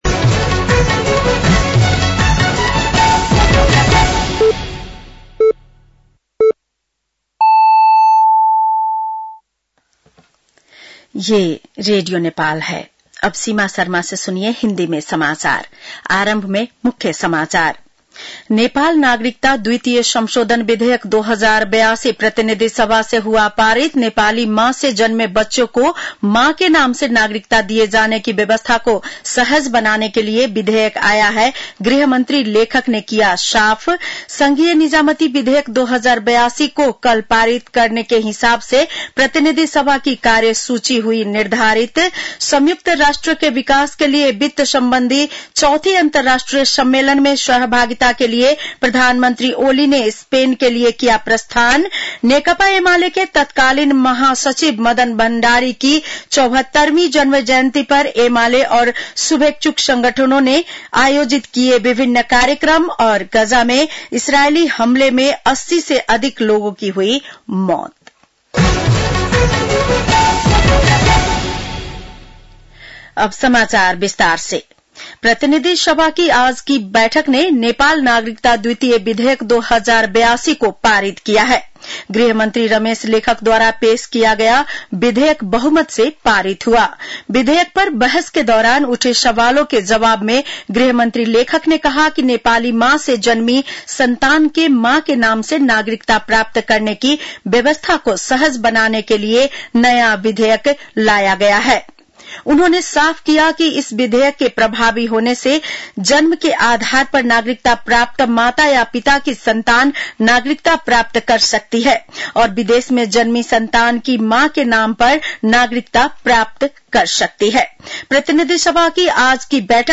बेलुकी १० बजेको हिन्दी समाचार : १४ असार , २०८२
10-pm-news-1-1.mp3